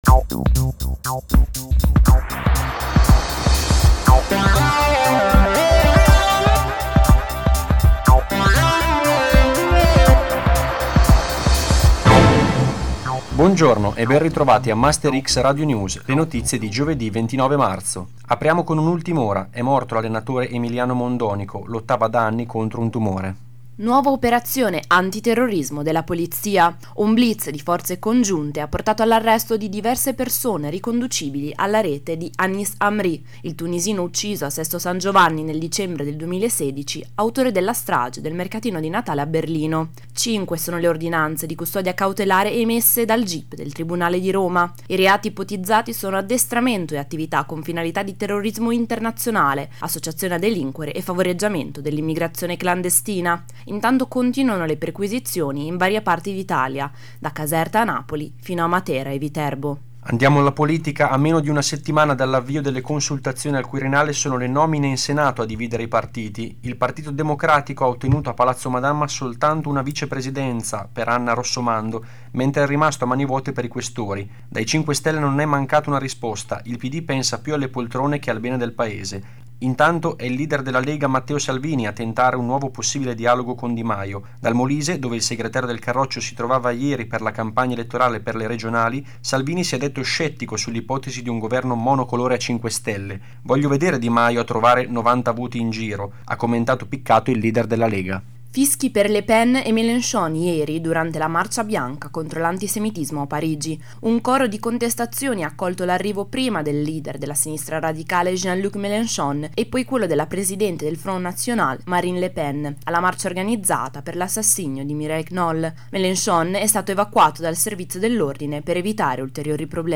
MASTERX RADIO NEWS – 29 MARZO